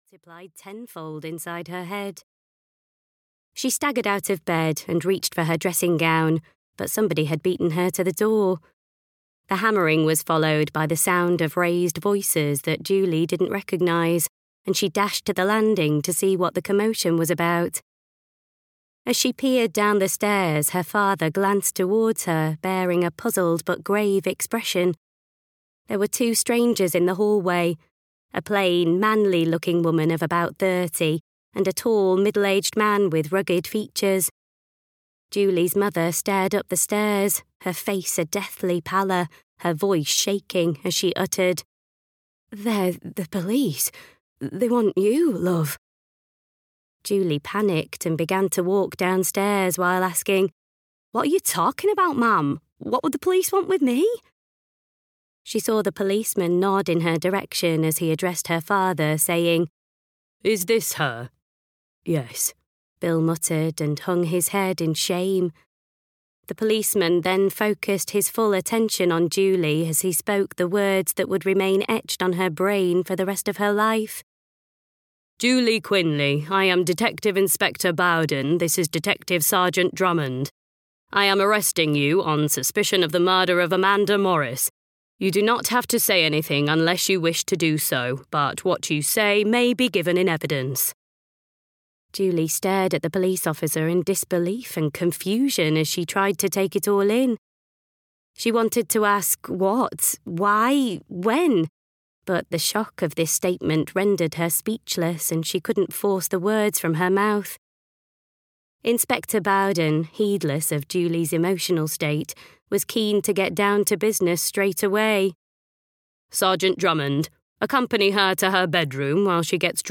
Slur (EN) audiokniha
Ukázka z knihy